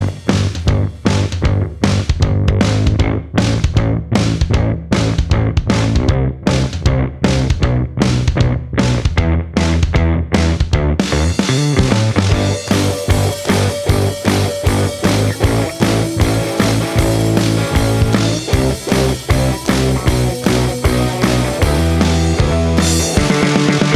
Minus Lead Guitar Indie / Alternative 3:33 Buy £1.50